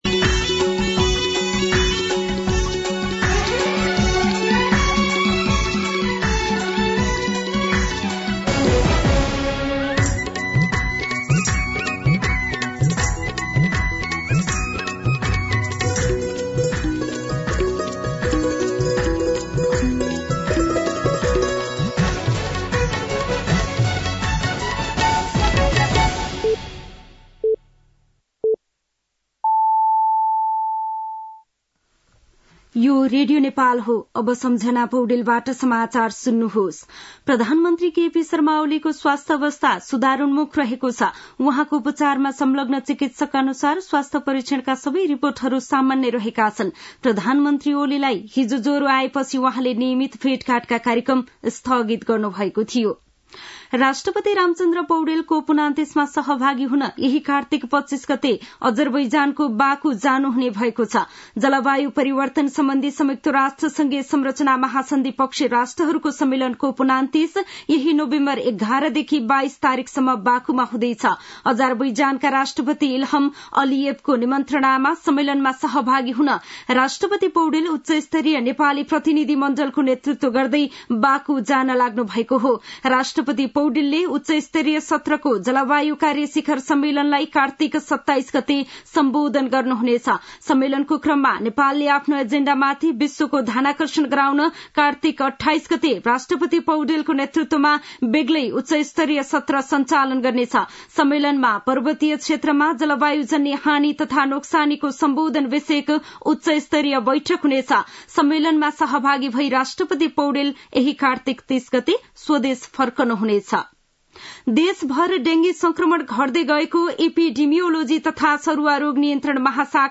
दिउँसो ४ बजेको नेपाली समाचार : २२ कार्तिक , २०८१